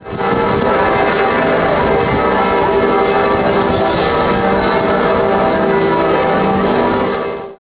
ringing of the bells (30.5k) from around the city.
bells_florence.wav